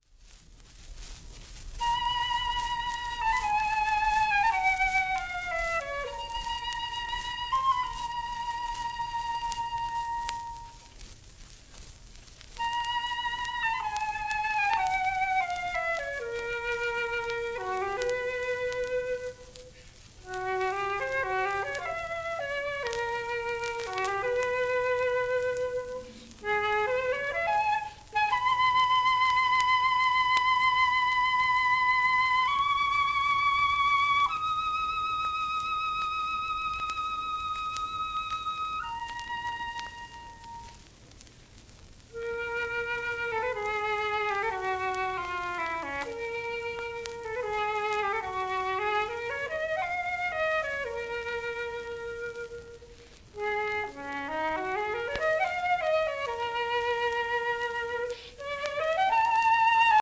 盤質A- *小キズ、薄いスレ
シェルマン アートワークスのSPレコード